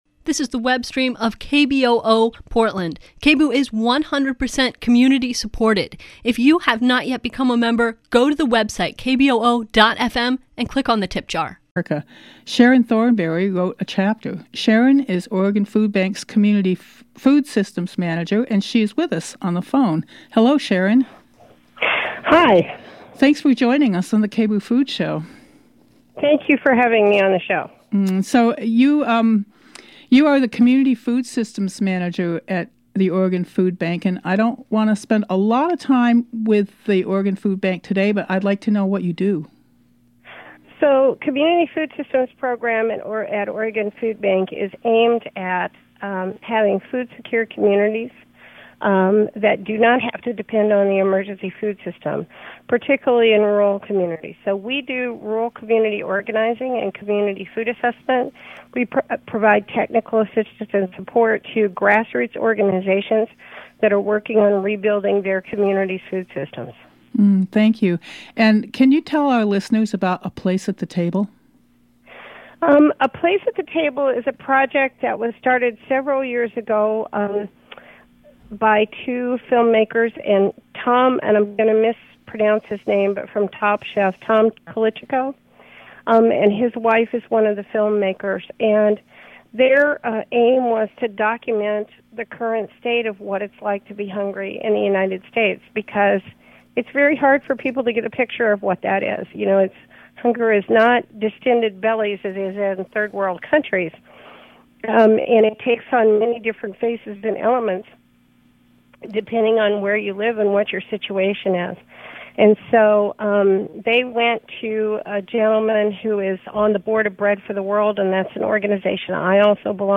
farmers market panel